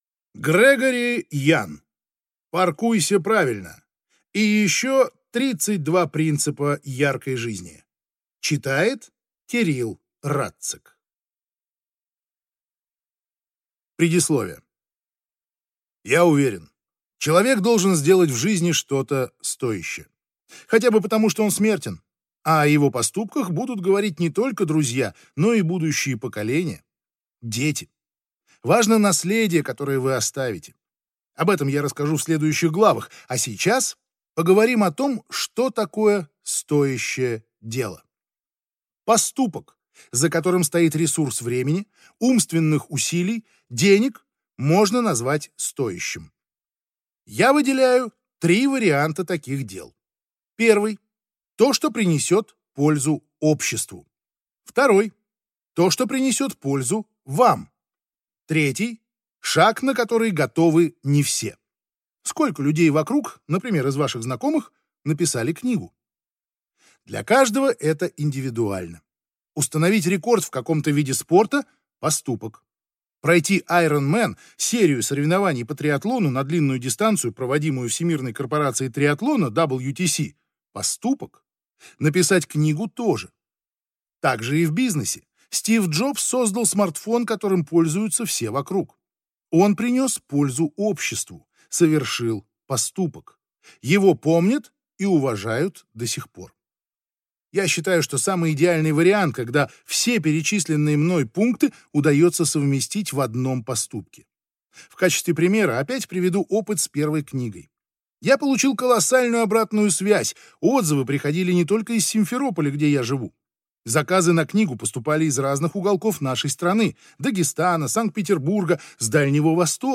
Аудиокнига Паркуйся правильно и еще 32 принципа яркой жизни | Библиотека аудиокниг